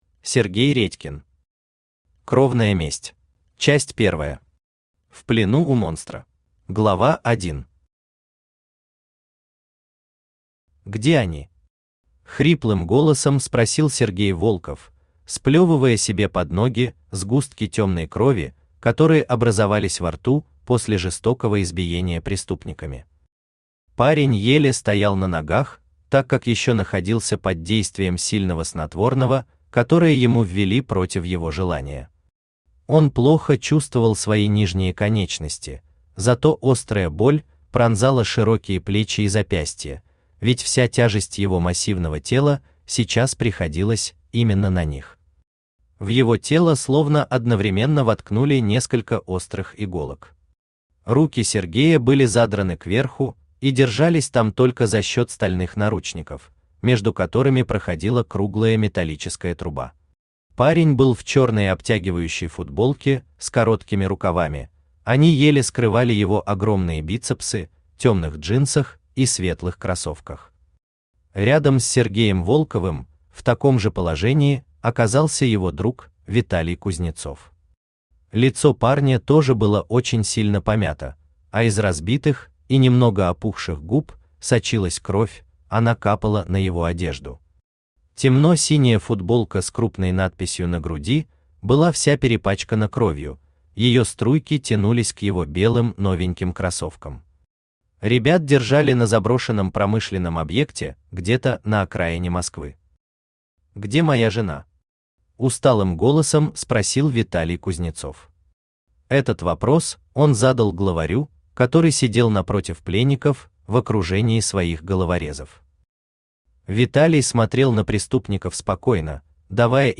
Аудиокнига Кровная месть | Библиотека аудиокниг
Aудиокнига Кровная месть Автор Сергей Редькин Читает аудиокнигу Авточтец ЛитРес.